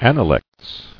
[an·a·lects]